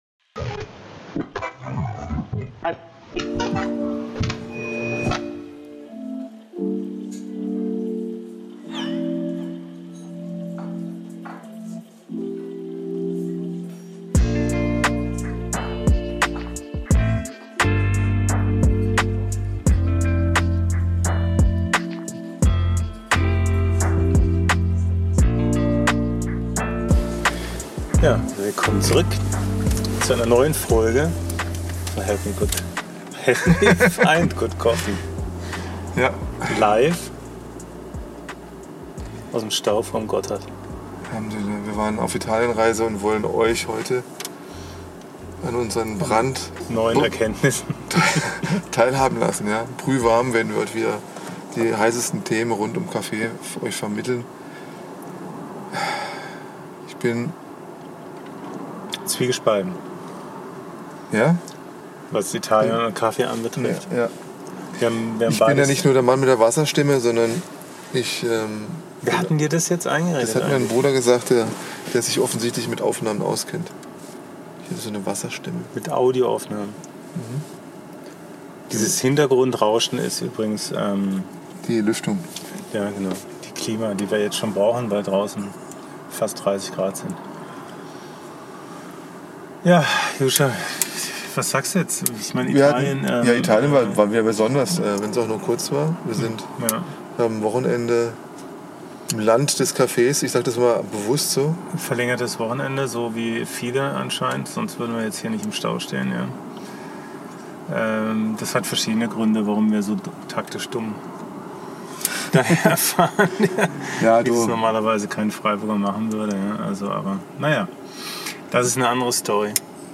im Auto vor dem Gotthard Tunnel im Stau.